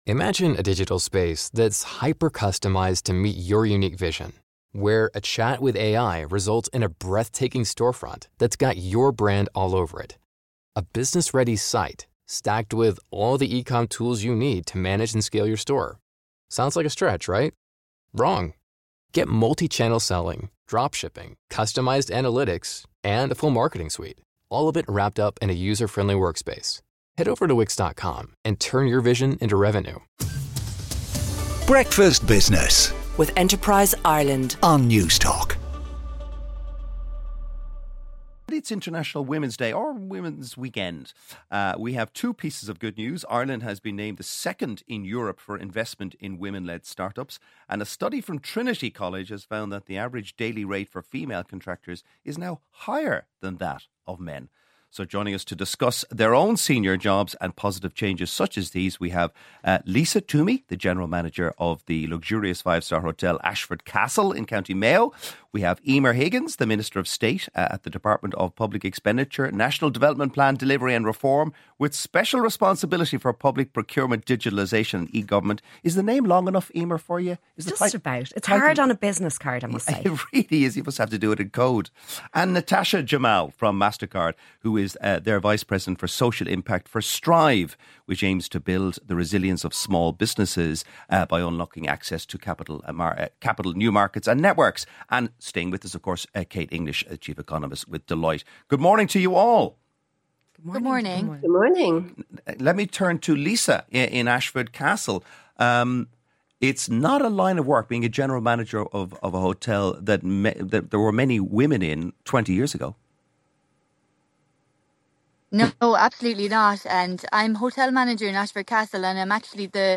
Breakfast Business with Joe Lynam - Leading women in business panel